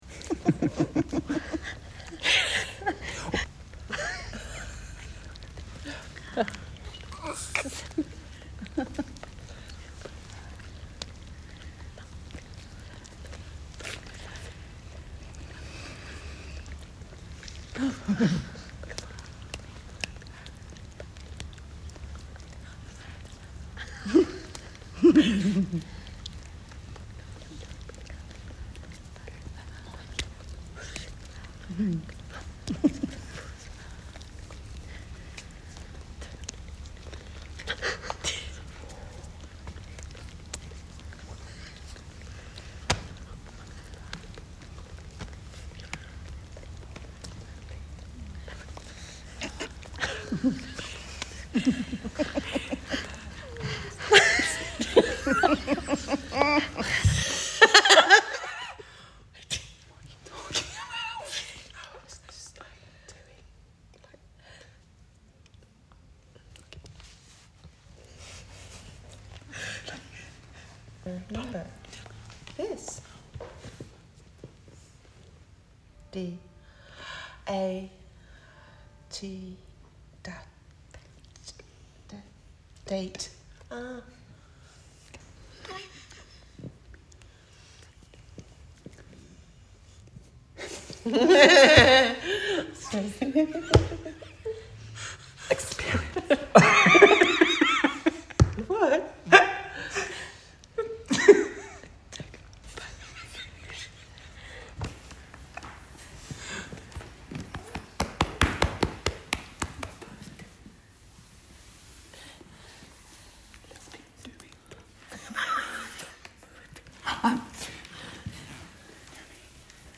en The Misperception of Sound by the Wales / Cymru Collective - over a week of research that explores how sound functions in a Deaf and hearing collective; the sounds of fluent sign language, the sounds of learning sign language, the attempt at movement and sign language in tandem, blindfolded movement translation of sensations and a lunch time drill. The work encapsulates the range of sound we work with as well as the observation of the continual hummmm of sound pollution that’s all around us. It’s recommended that you play this on a large speaker.
en Vibrations